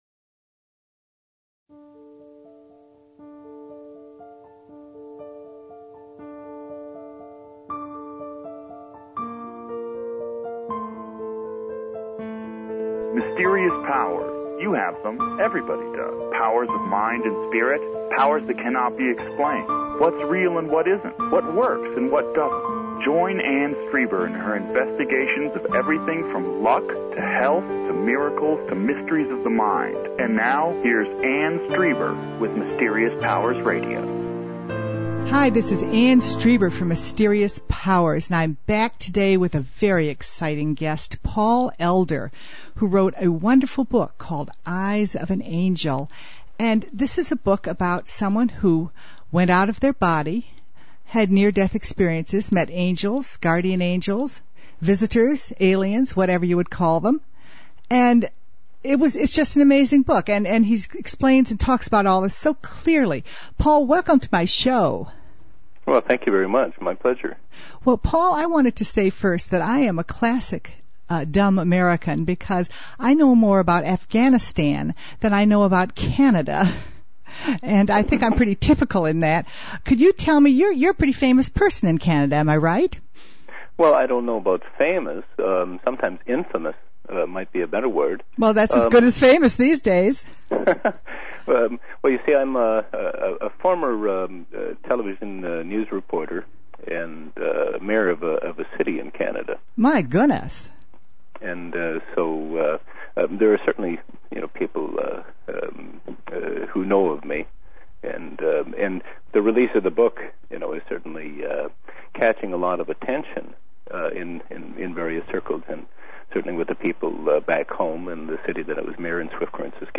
Where else will you hear something like this, with both the interviewer and the guest sharing experiences?